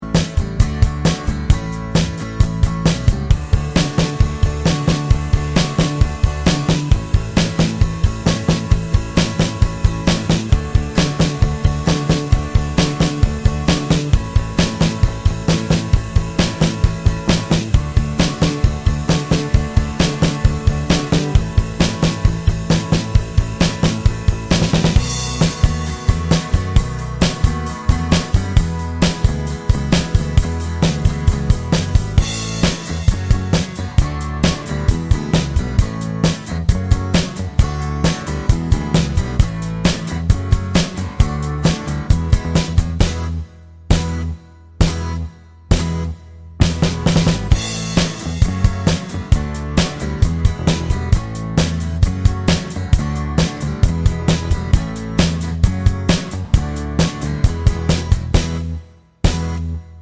no Backing Vocals Rock 'n' Roll 2:16 Buy £1.50